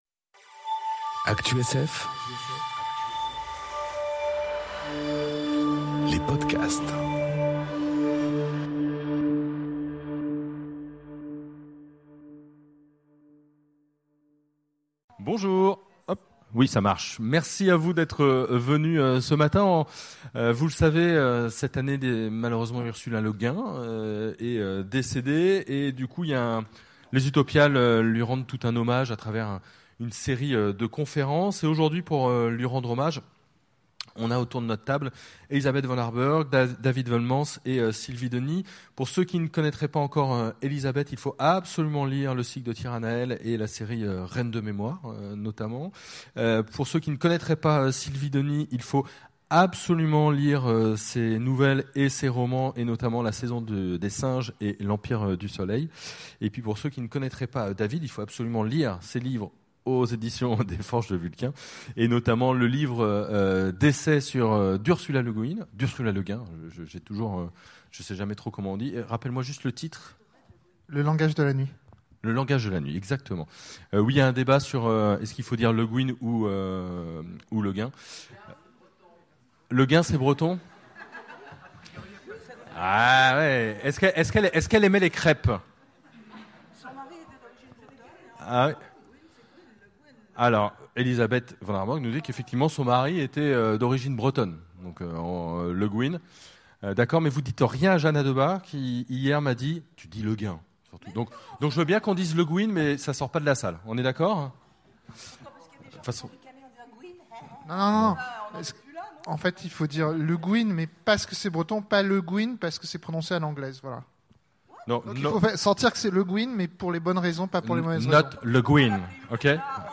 Conférence Ursula K. Le Guin, La Main gauche de la nuit, hommage enregistrée aux Utopiales 2018